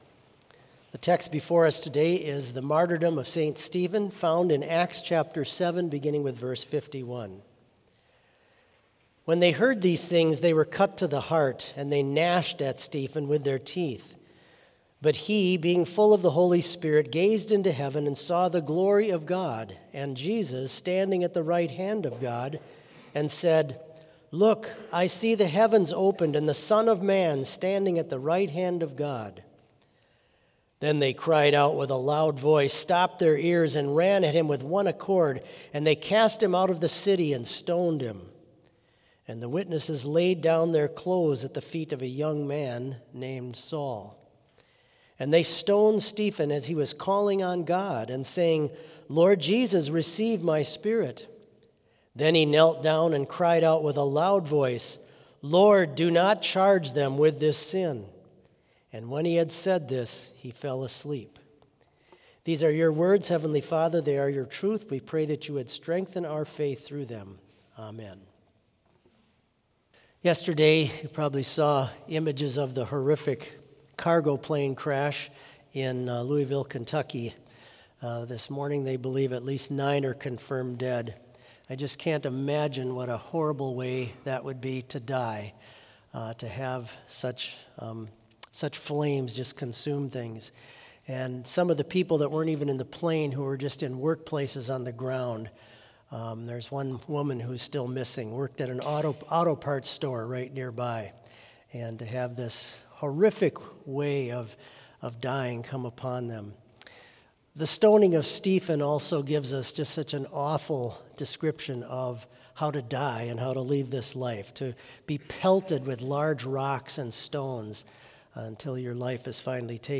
Sermon Only
This Chapel Service was held in Trinity Chapel at Bethany Lutheran College on Wednesday, November 5, 2025, at 10 a.m. Page and hymn numbers are from the Evangelical Lutheran Hymnary.